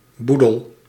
Ääntäminen
IPA: /mas/